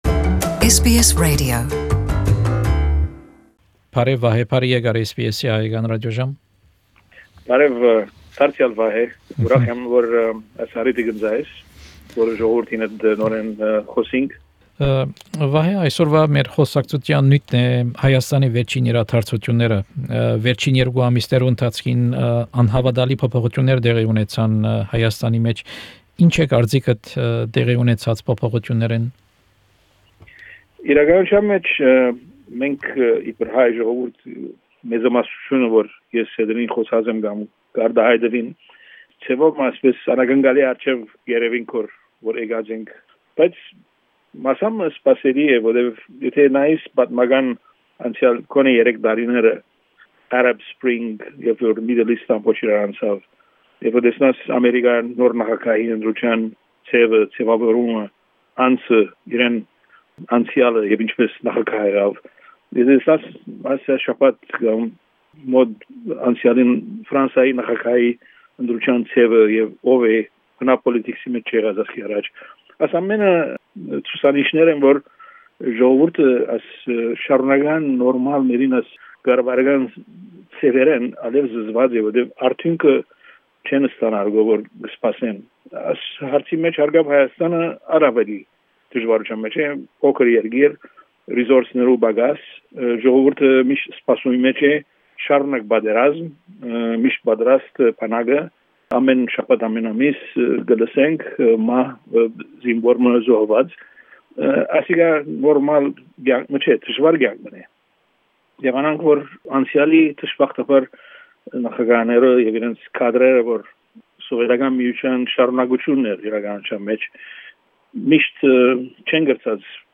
Հարցազրոյց